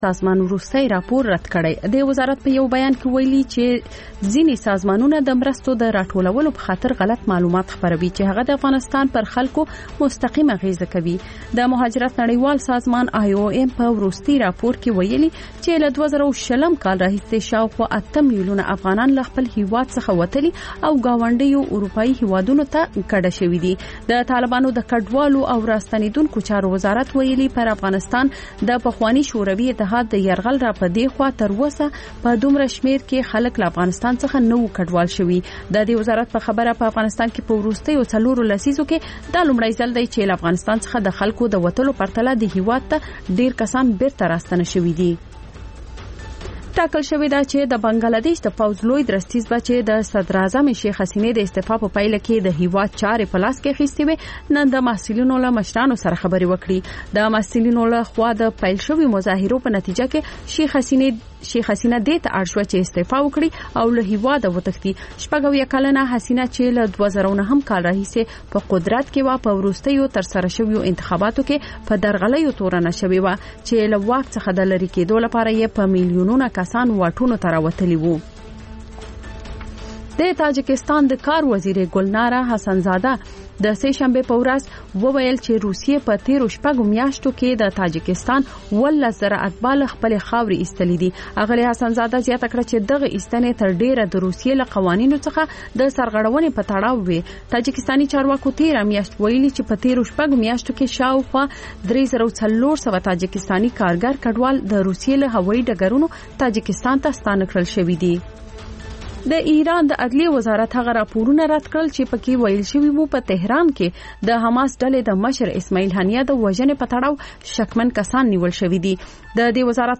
لنډ خبرونه - تاندې څانګې (تکرار)